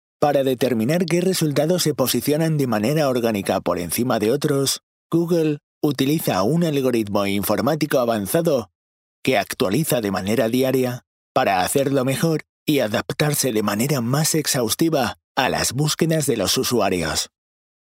Locutor profesional en Español con estudio de grabación y disponibilidad 24/7.
Sprechprobe: eLearning (Muttersprache):
Professional spanish voice over specialised in radio ads and corporate messages.